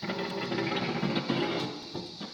rusty-hatch-short.ogg